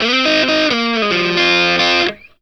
BLUESY3 F 90.wav